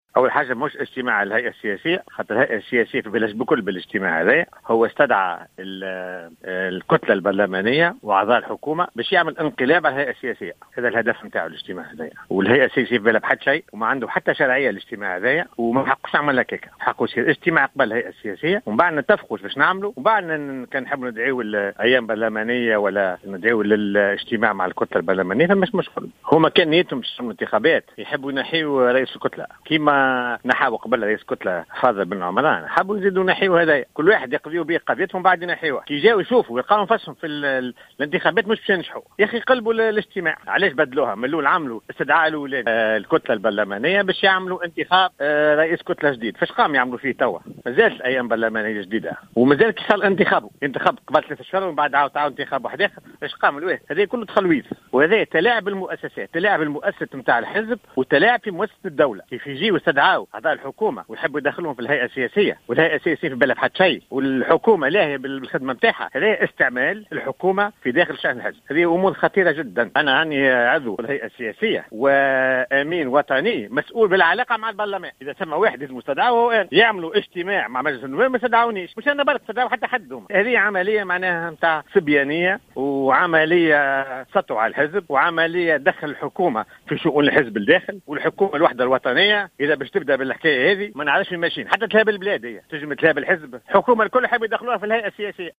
ووصف عضو الهيئة السياسية لحركة نداء تونس المكلف بالعلاقة مع البرلمان فوزي اللومي في تصريح للجوهرة "اف ام" اجتماع اليوم الذي دعا له المدير التنفيذي للحركة حافظ قائد السبسي بالإنقلابي مؤكدا أن الهدف من هذا الاجتماع هو السطو على الحزب.